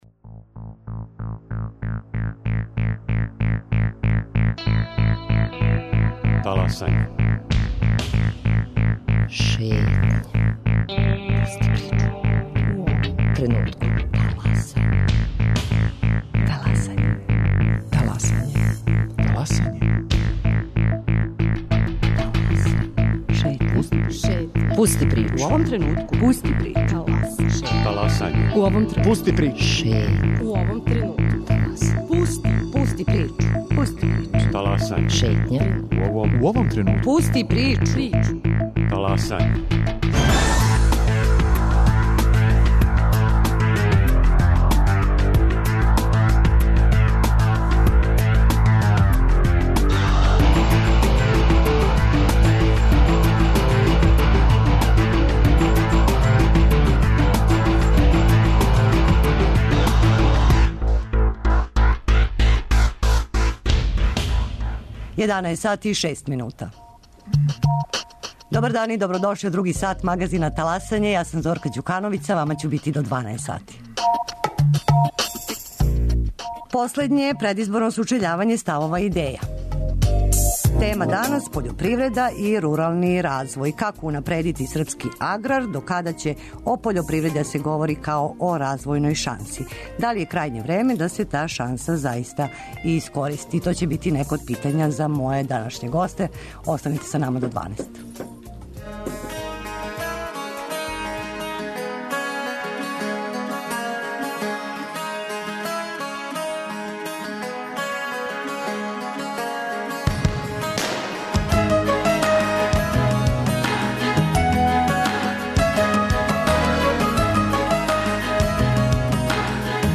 Предизборно сучељавање ставова и идеја. Тема пољопривреда и рурални развој.
Позвани представници изборних листа: